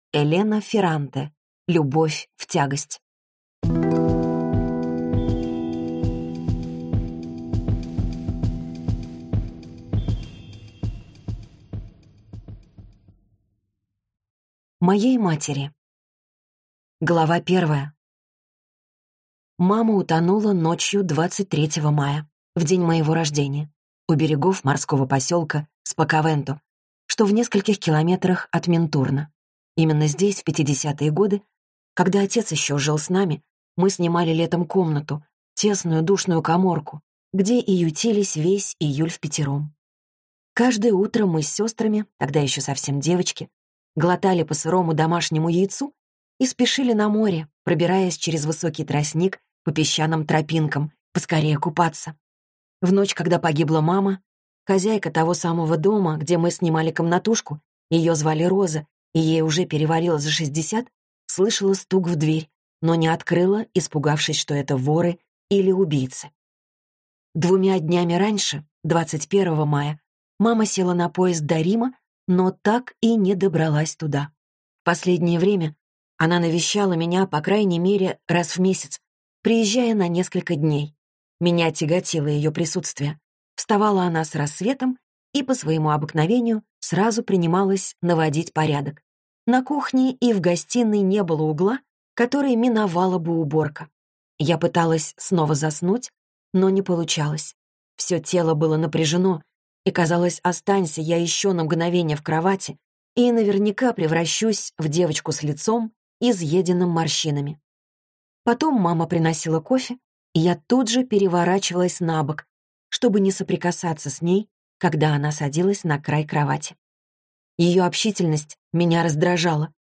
Аудиокнига Любовь в тягость - купить, скачать и слушать онлайн | КнигоПоиск